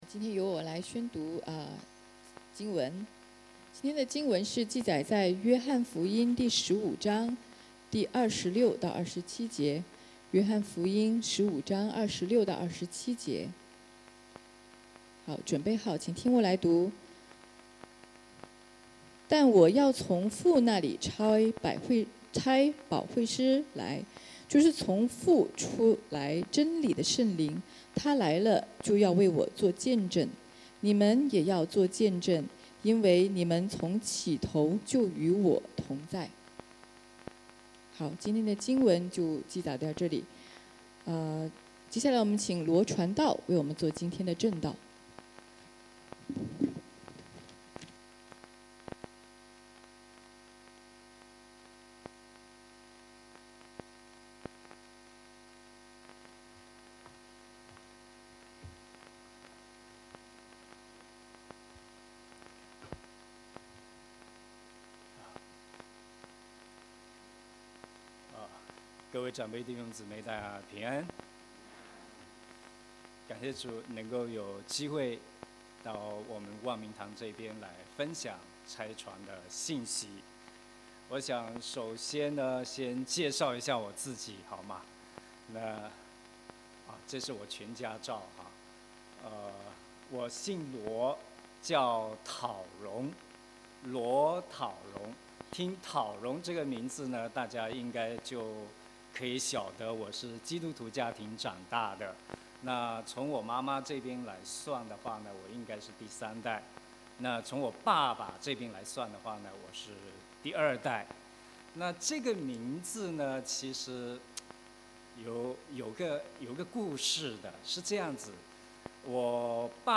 8Dec2024-Chinese-Sermon-家事分享.mp3